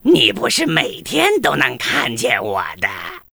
文件 文件历史 文件用途 全域文件用途 Gbn_tk_03.ogg （Ogg Vorbis声音文件，长度3.4秒，108 kbps，文件大小：44 KB） 源地址:游戏语音 文件历史 点击某个日期/时间查看对应时刻的文件。